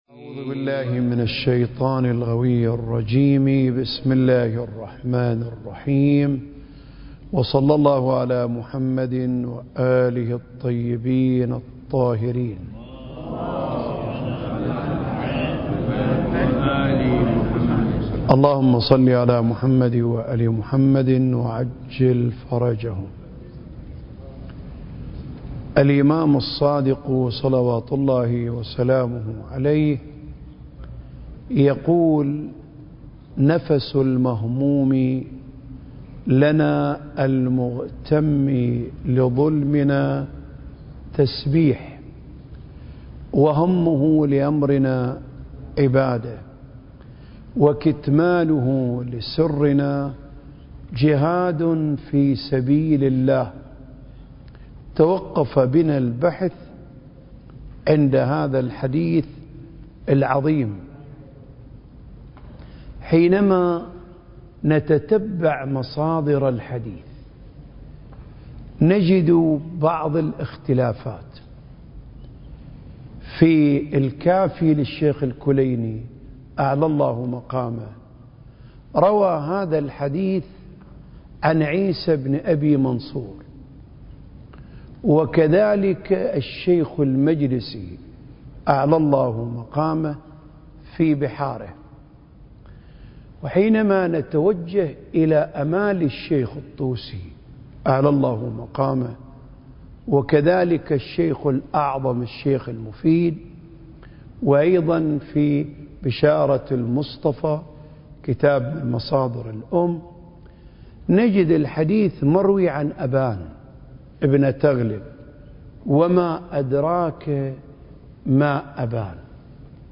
سلسلة محاضرات: آفاق المعرفة المهدوية (9) المكان: الأوقاف الجعفرية بالشارقة التاريخ: 2023